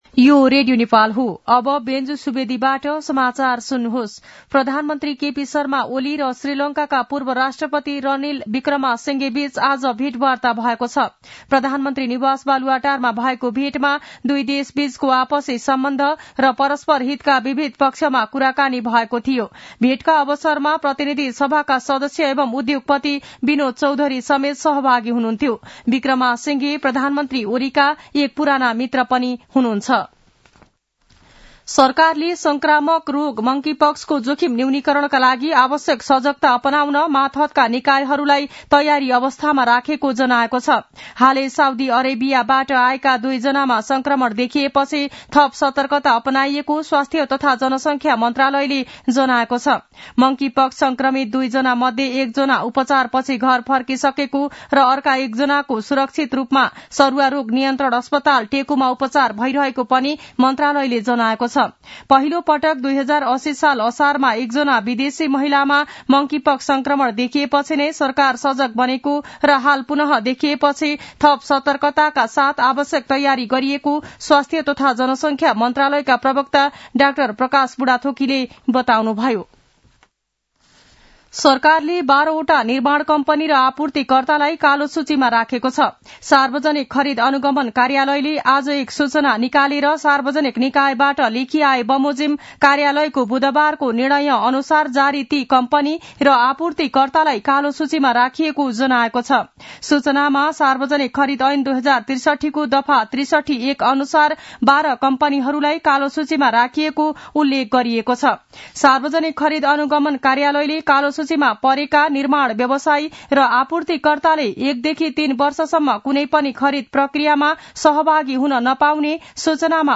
दिउँसो १ बजेको नेपाली समाचार : १९ पुष , २०८१
1-pm-nepali-news-1-1.mp3